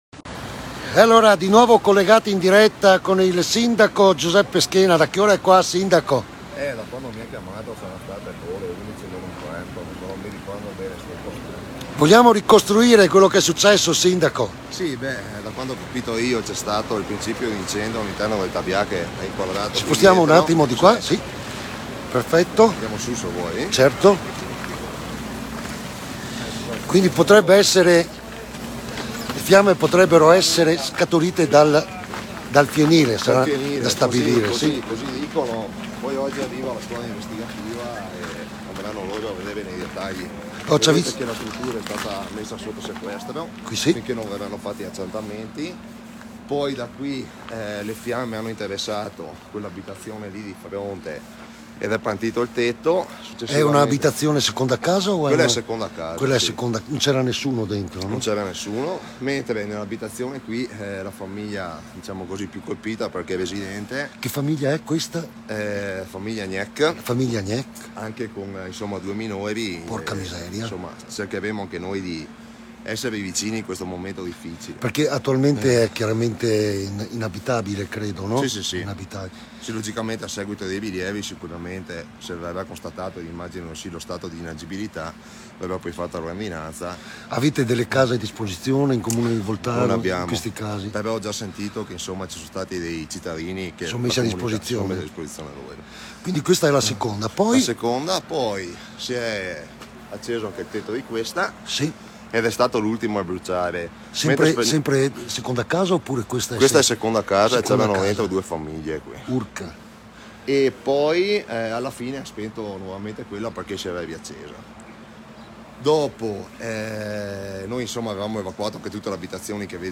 IL SINDACO E UOMO DELLA PROTEZIONE CIVILE, GIUSEPPE SCHENA, ORE 7.00, SITUAZIONE SOTTO CONTROLLO